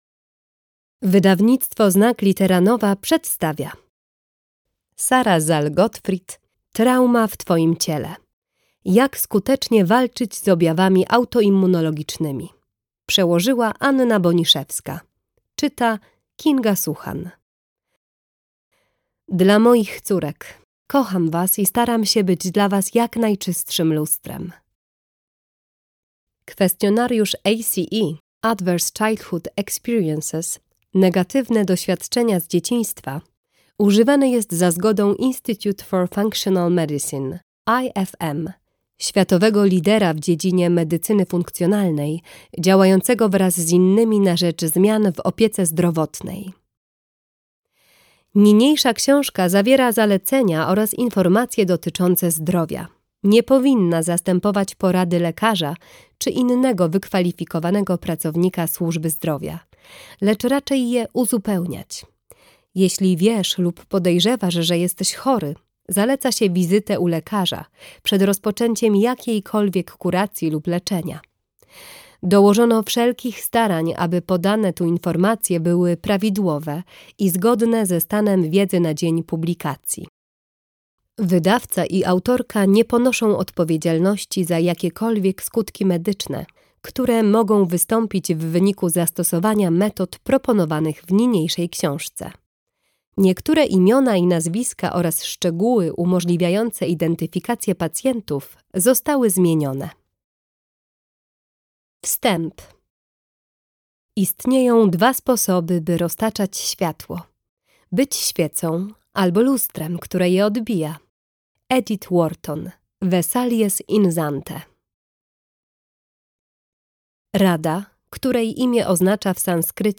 Jak skutecznie walczyć z objawami autoimmunologicznymi - Sara Szal Gottfried - audiobook - Legimi online